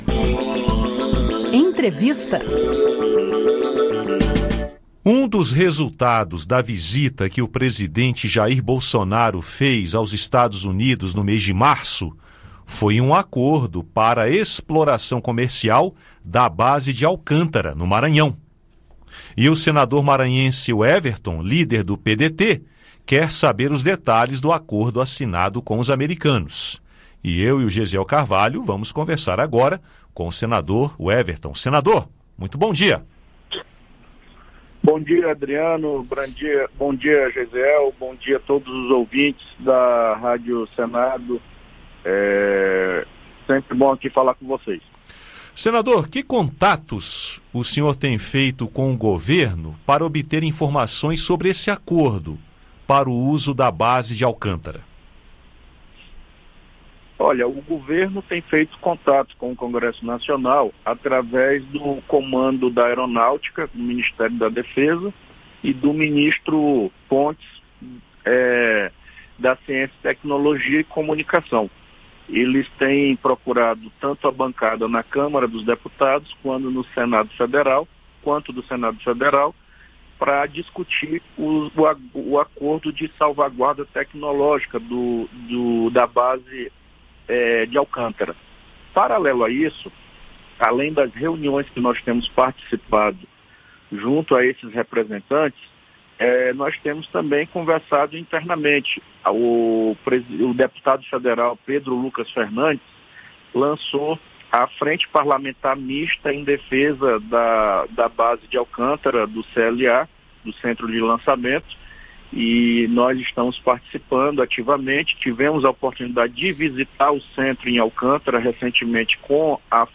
O Senado deve analisar o acordo assinado pelo presidente Jair Bolsonaro que prevê a utilização da Base de Alcântara, no Maranhão, por outros países. Para o senador Weverton (PDT-MA), essa medida deve vir acompanhada de uma espécie de pagamento de “royalties” para o estado e para o município. Ouça a entrevista do senador ao programa Conexão Senado.